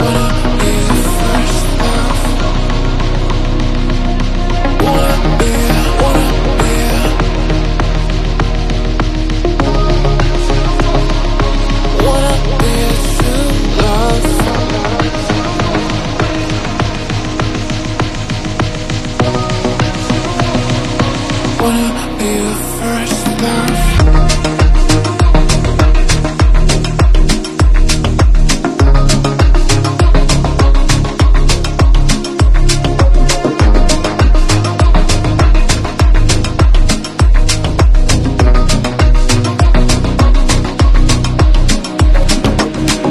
numa versão mais lenta?